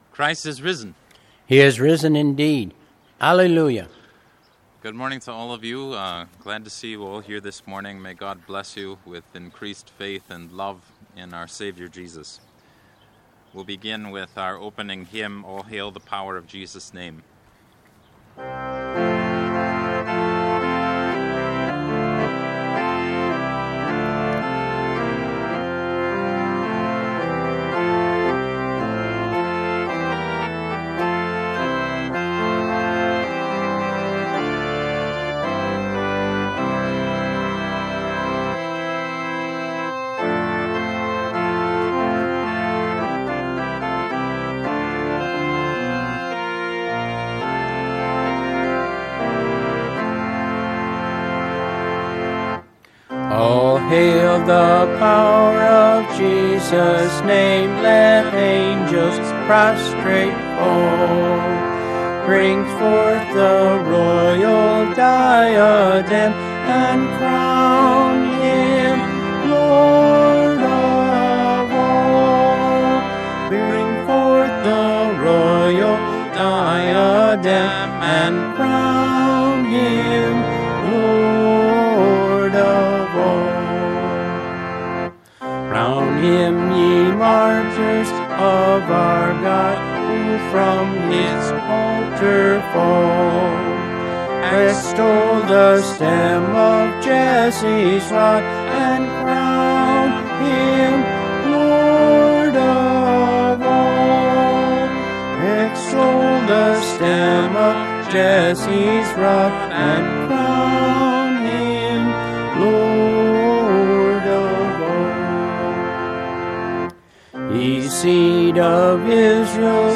200503 Easter 4 Drive in Service